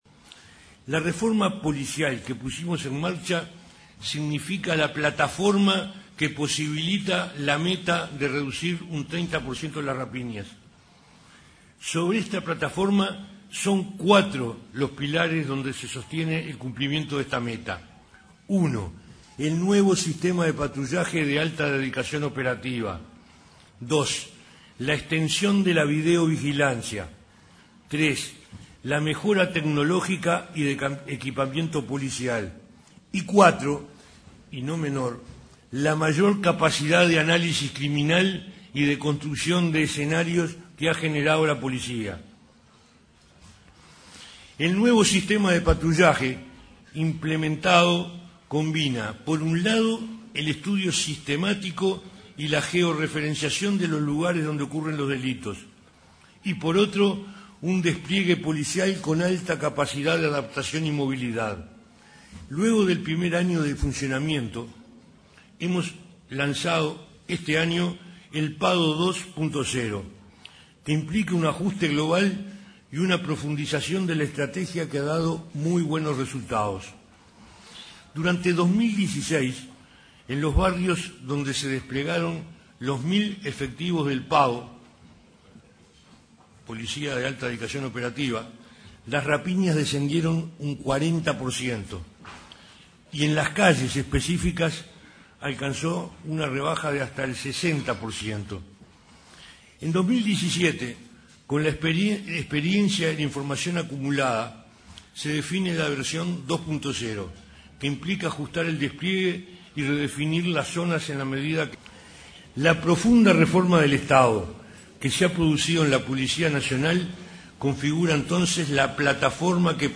El ministro Eduardo Bonomi dijo en ADM que entre diciembre de 2018 y marzo 2019 esperan alcanzar un 24% de baja de rapiñas.